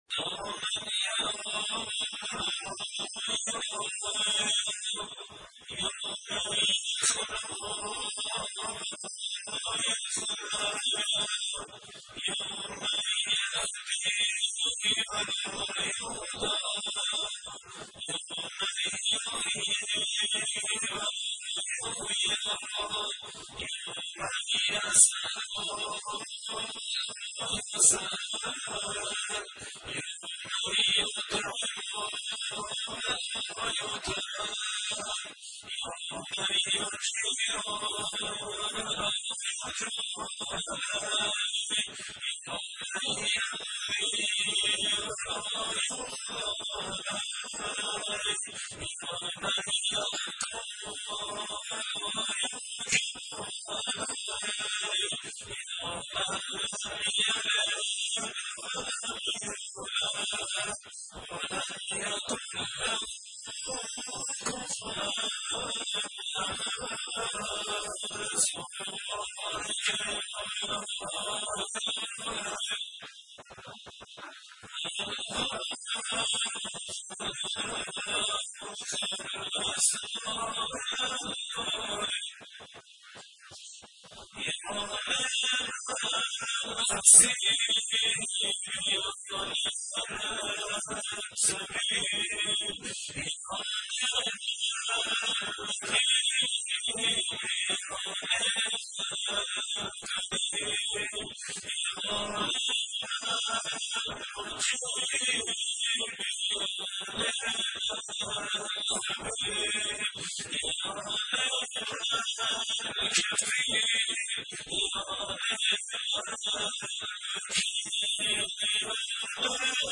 قرائت فراز هایی از دعای جوشن کبیر